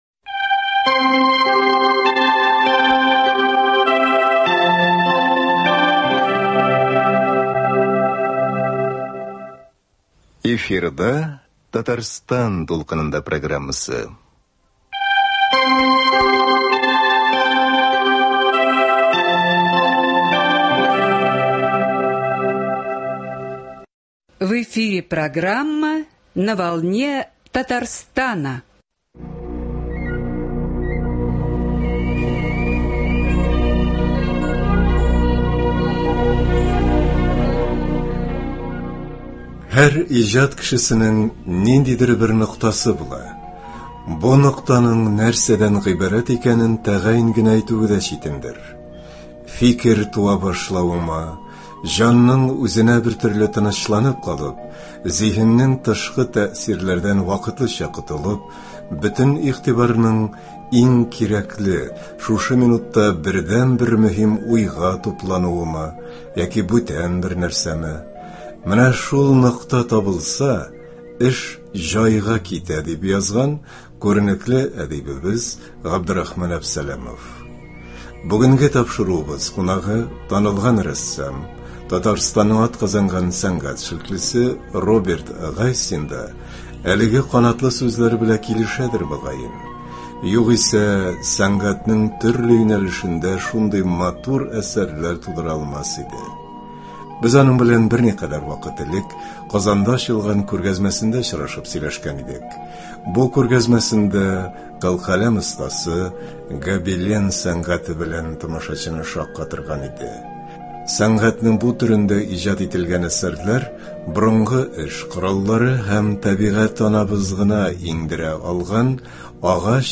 Студиябез кунагы